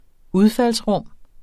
udfaldsrum substantiv, intetkøn Bøjning -met, -, -mene Udtale [ ˈuðfals- ] Betydninger 1.